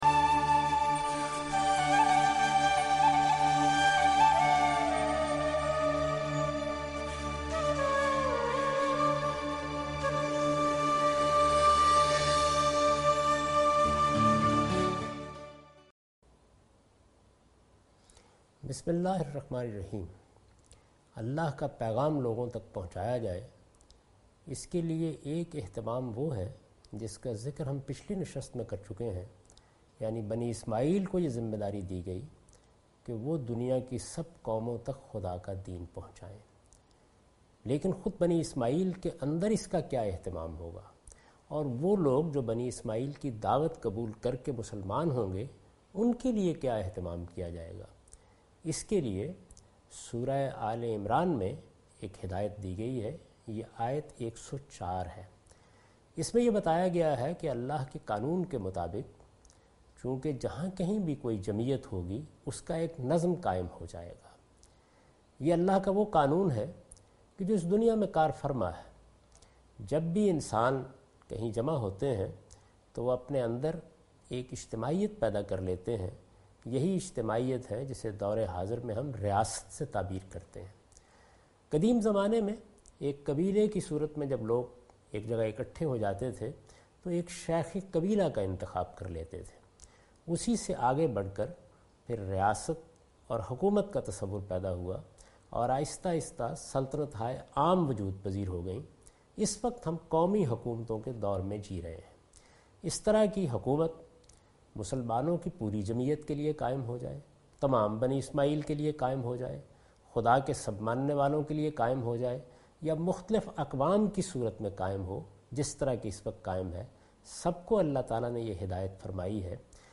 This series contains the lecture of Javed Ahmed Ghamidi delivered in Ramzan.